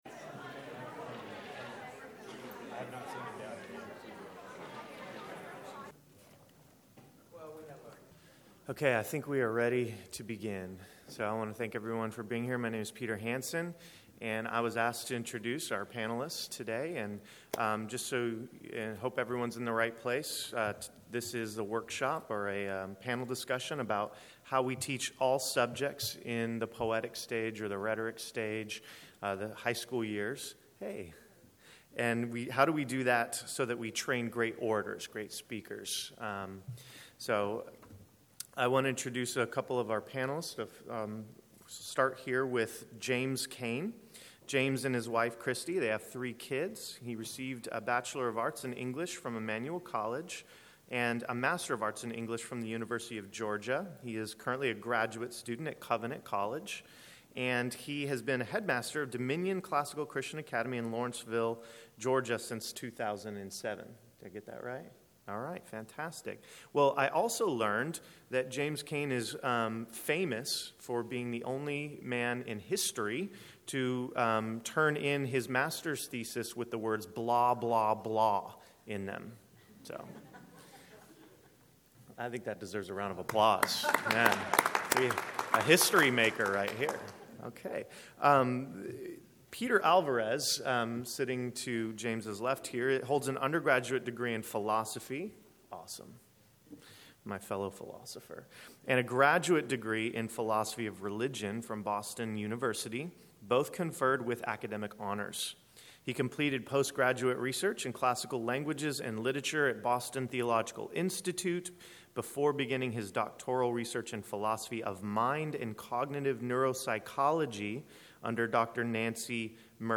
2012 Workshop Talk | 1:01:27 | K-6, Rhetoric & Composition
Each panelist will make opening remarks. Following these remarks, the panel will answer questions from the audience.
How Do We Teach All Subjects in the Poety Stage So That We Train Great Orators Panel Discussion.mp3